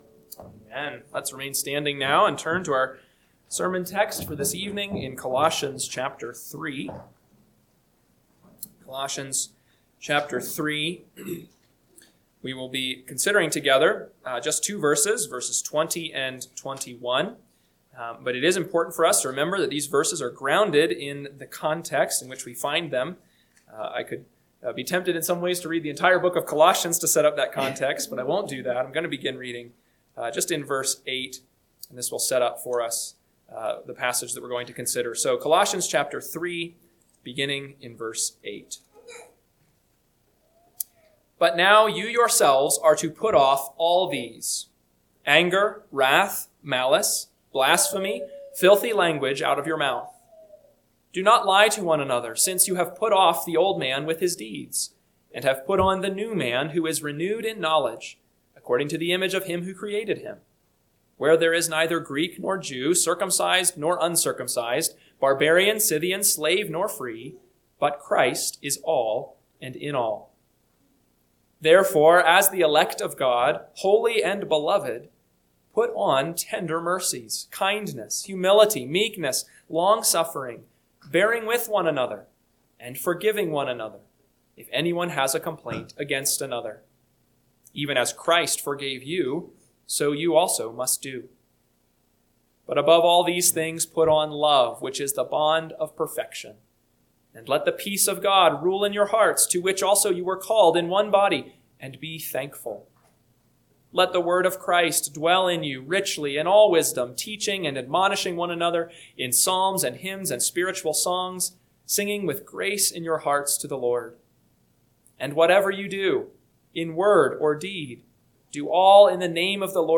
PM Sermon – 4/19/2026 – Colossians 3:20-21 – Northwoods Sermons